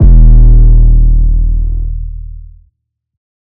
TM88 808.wav